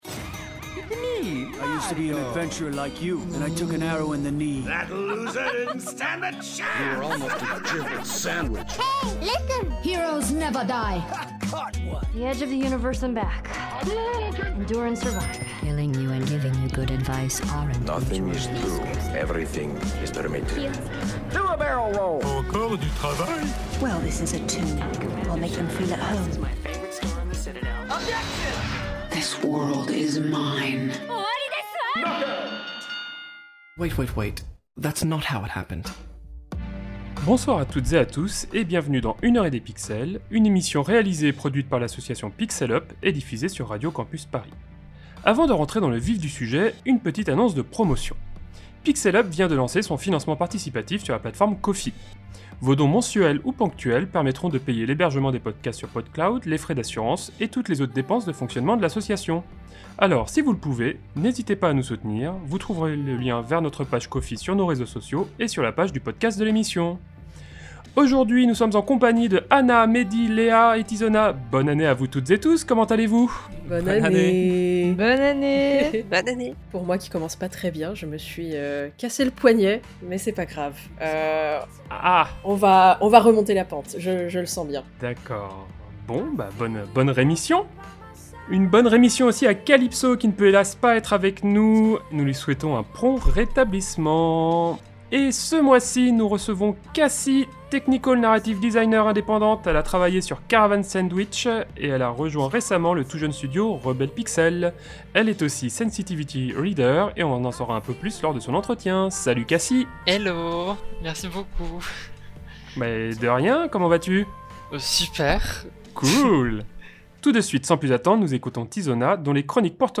Émission diffusée le 25 janvier 2025 sur Radio Campus Paris.